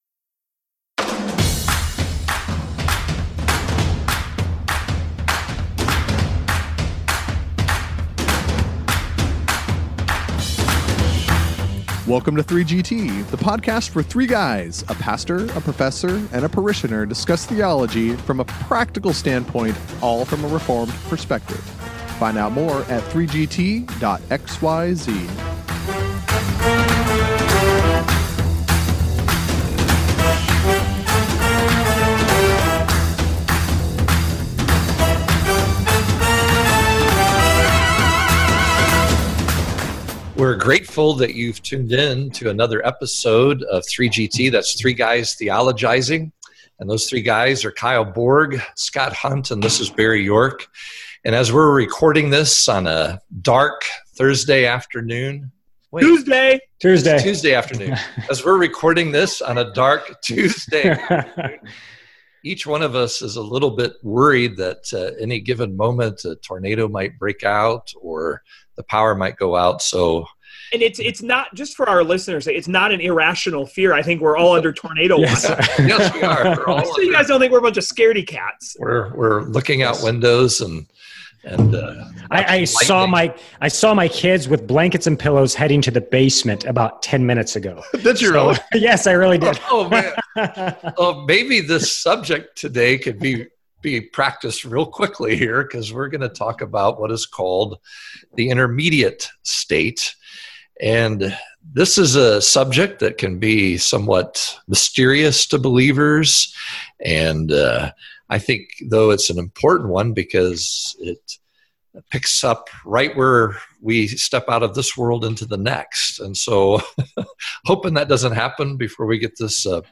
As they recorded this episode, the guys were hunkered down under tornado watches in their respective states.